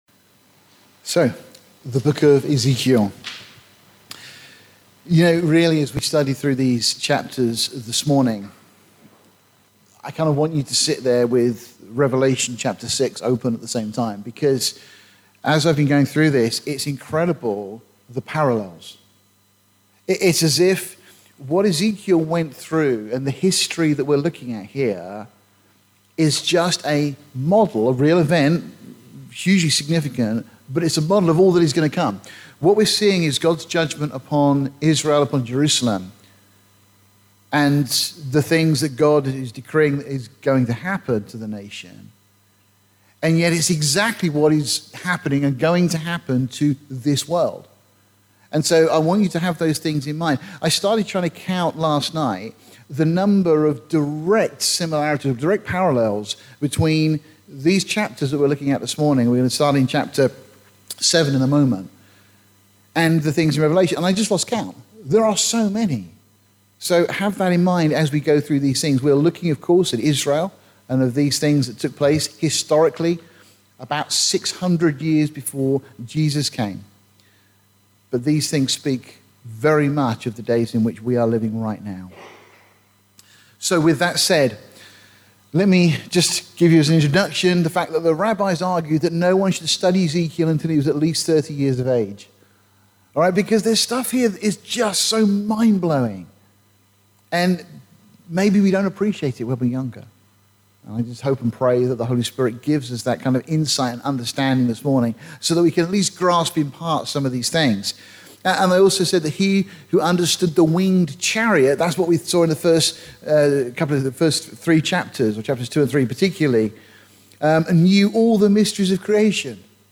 Comparisons with modern day events and trends are prominent in the latter half of the sermon.